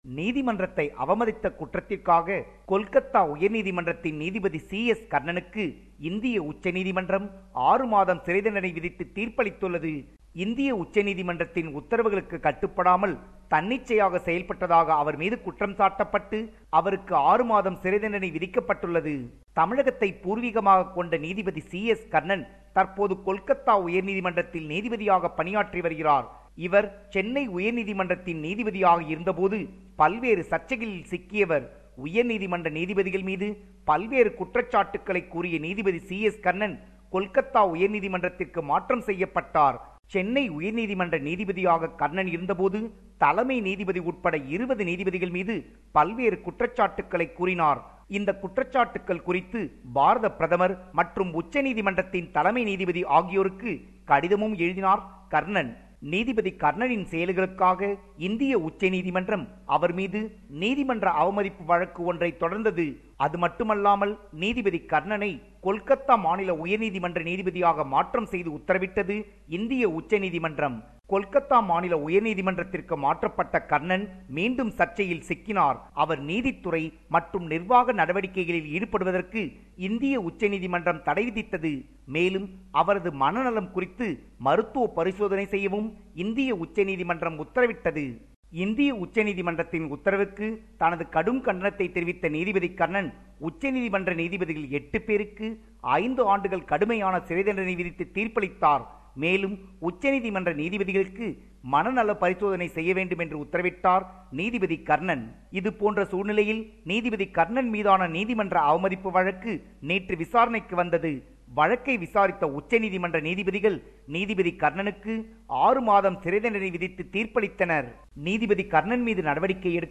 compiled a report focusing on major events/news in Tamil Nadu